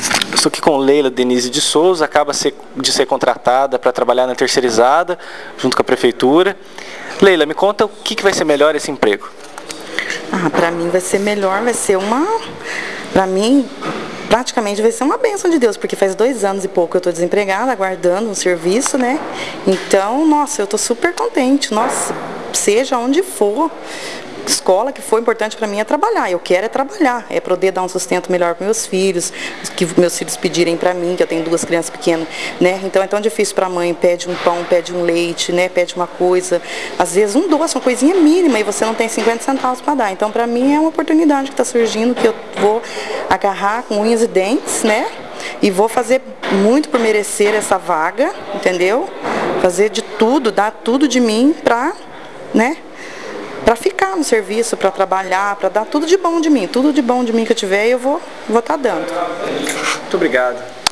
Confira depoimentos de três dos novos servidores prestadores de serviço para Educação.